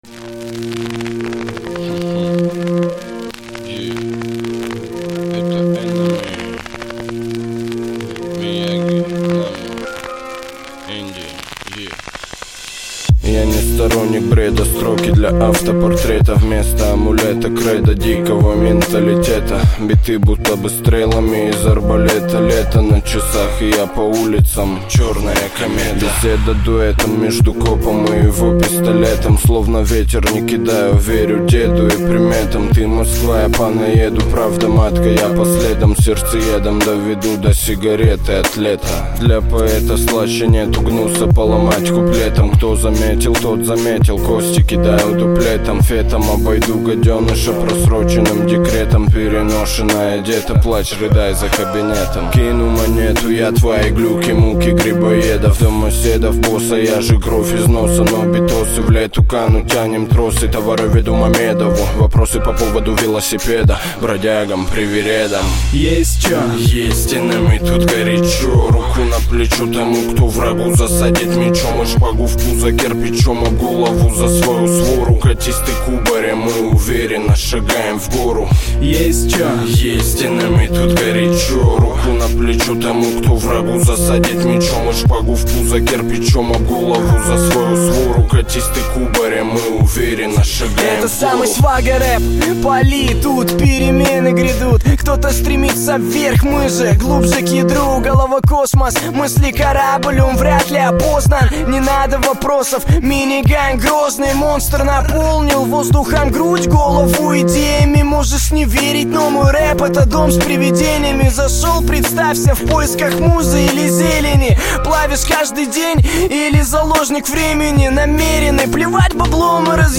Жанр: Жанры / Русский рэп